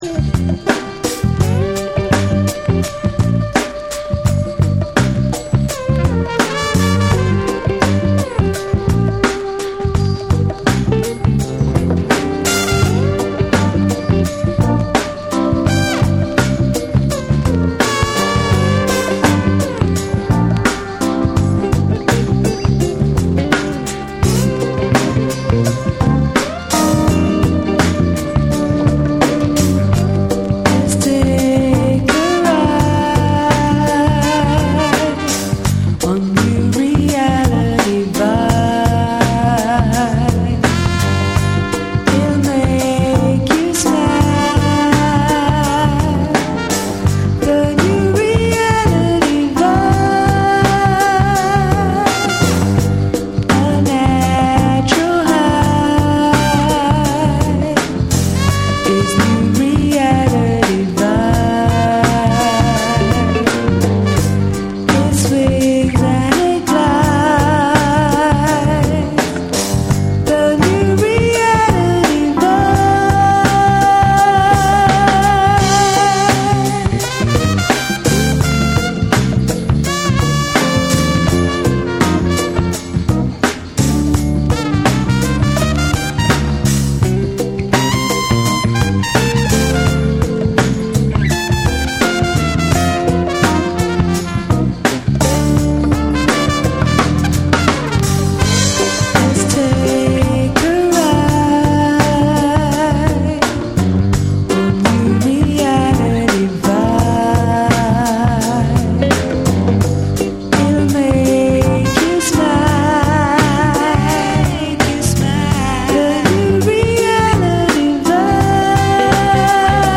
軽やかでジャジーな演奏に、ソウルフルで気品あるヴォーカルが溶け合う
BREAKBEATS